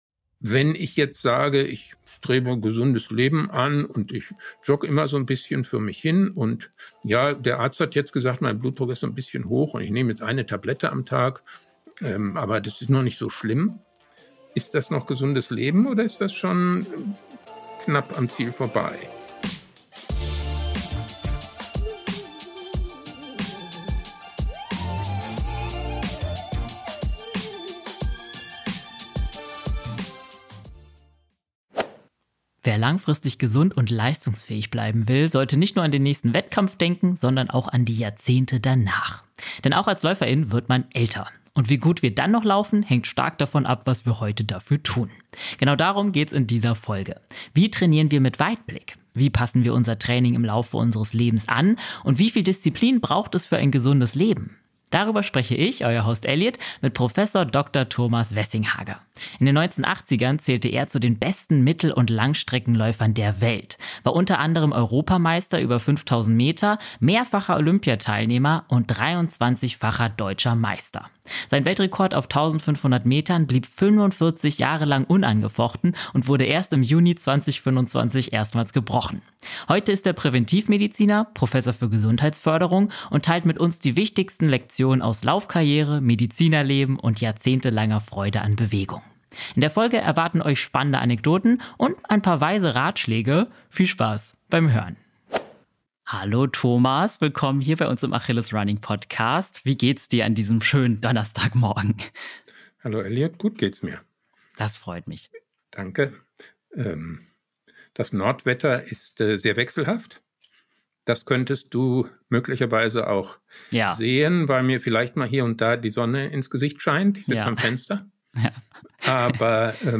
In dieser Folge sprechen wir mit Prof. Dr. Thomas Wessinghage, Präventivmediziner und ehemaliger Weltklasseläufer, über die wichtigsten Stellschrauben für ein langes, gesundes Läuferleben. Er erklärt, wie sich Leistungsfähigkeit im Alter verändert, warum Disziplin ein Schlüssel für nachhaltigen Trainingserfolg ist und welche Rolle Ernährung, Regeneration und mentale Einstellung dabei spielen.